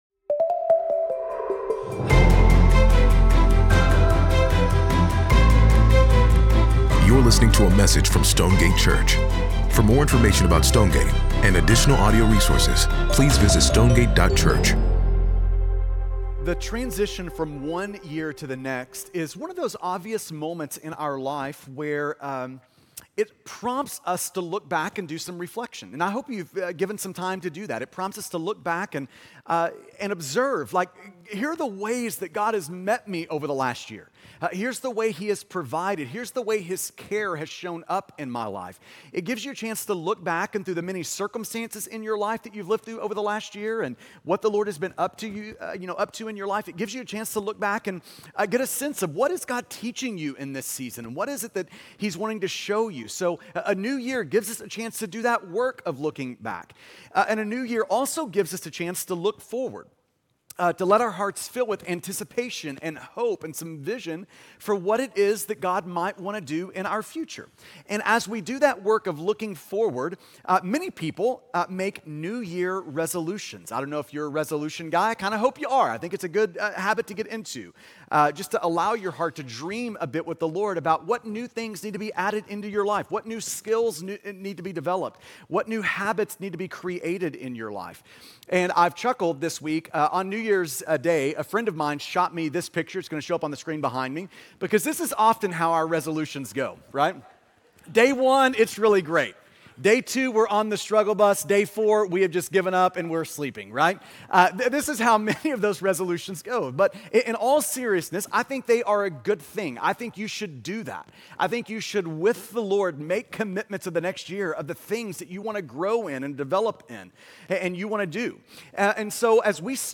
AAE 2 _ Sermon AUDIO.mp3